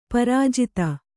♪ parājita